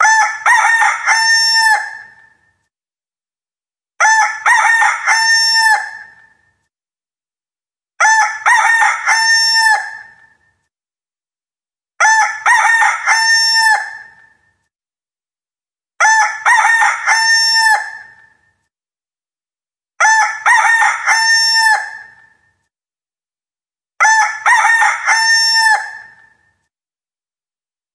Categoria Alarmes